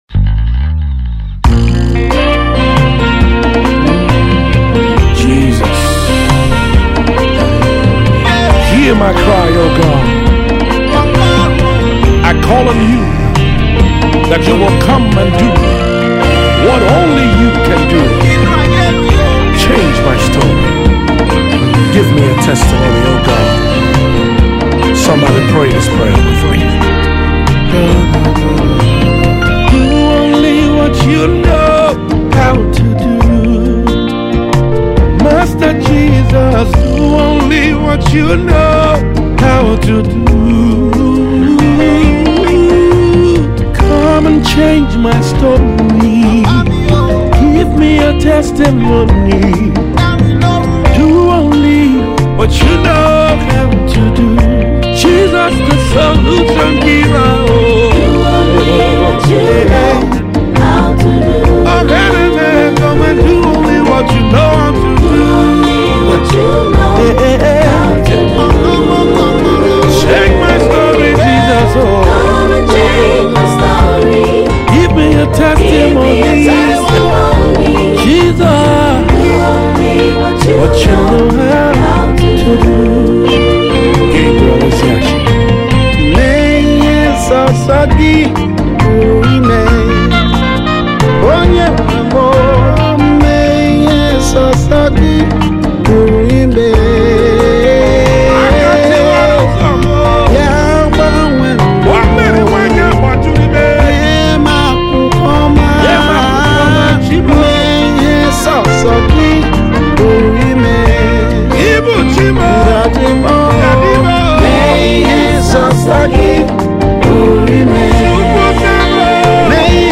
Yoruba Chants